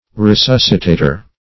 Resuscitator \Re*sus"ci*ta`tor\, n. [L.]
resuscitator.mp3